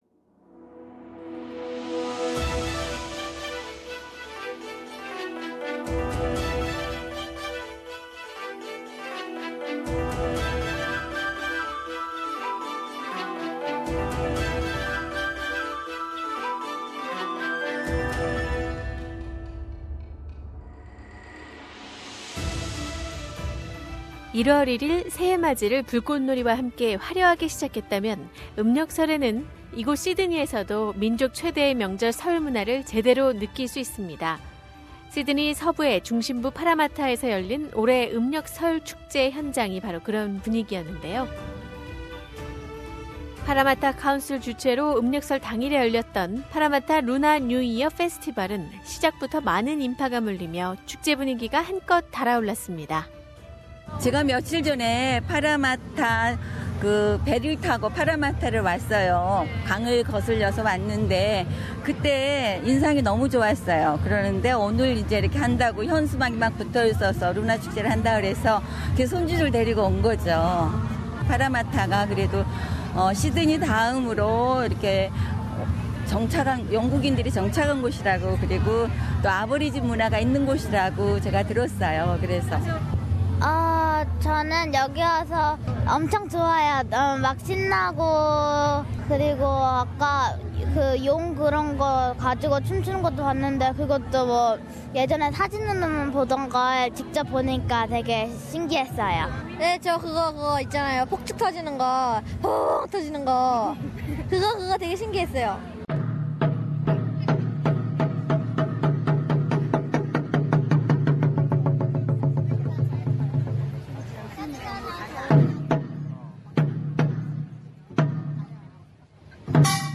2018 파라마타 음력설 축제 현장